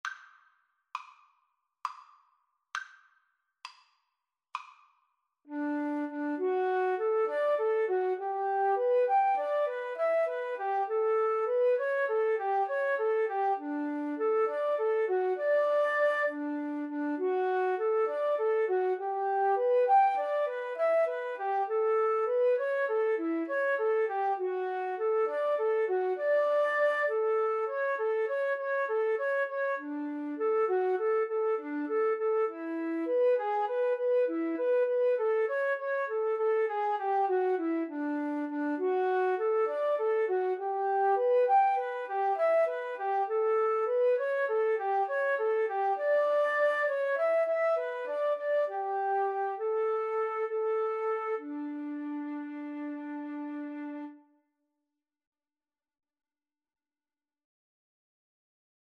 Free Sheet music for Flute Duet
D major (Sounding Pitch) (View more D major Music for Flute Duet )
Moderato
9/8 (View more 9/8 Music)